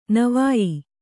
♪ navāyi